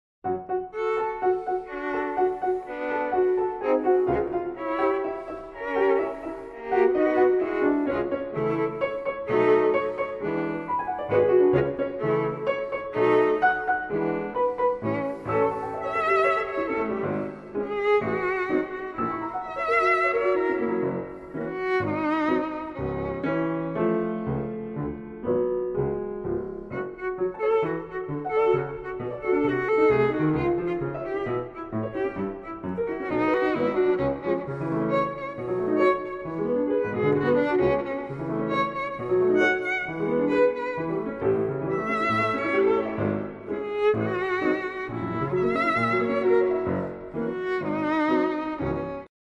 violin
piano
in D minor Op. 108
e con sentimento Wav.